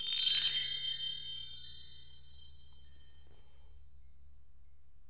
FRBELLTREE-S.WAV